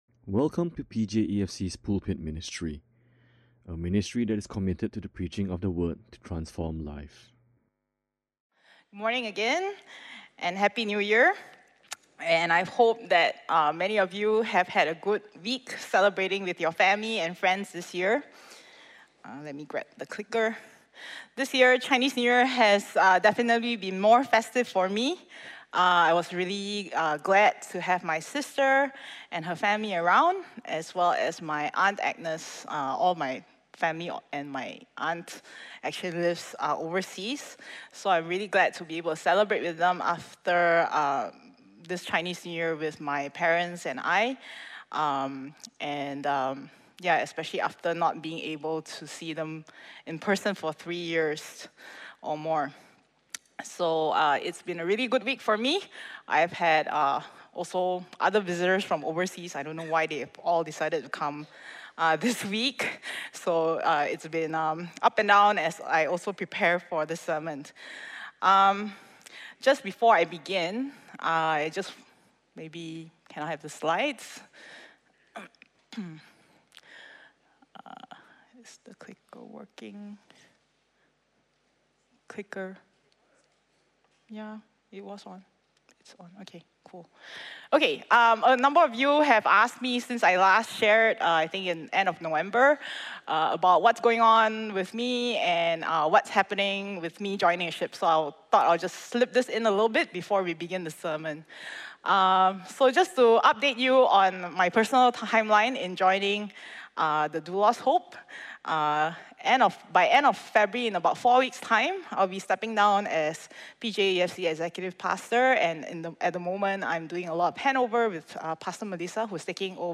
Listen to Sermon Only